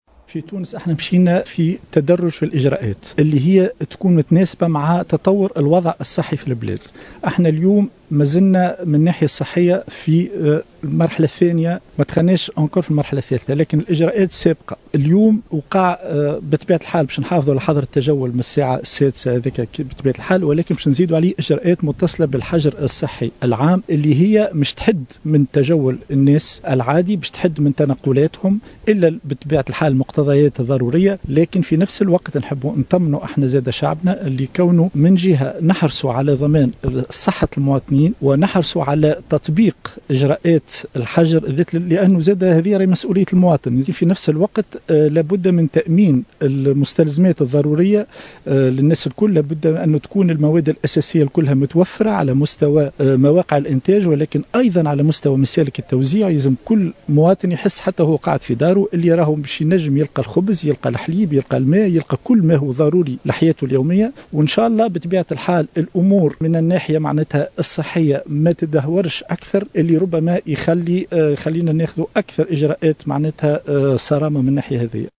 و لم يستبعد الحزقي في تصريح إعلامي خلال زيارته مساء اليوم إلى القاعدة العسكرية بالعوينة، لتفقّد جاهزية التشكيلات العسكرية المشاركة في مهمّة منع الجولان، اللجوء إلى اتخاذ إجراءات صارمة أكثر في صورة تدهور الوضعية الصحية، حسب تعبيره.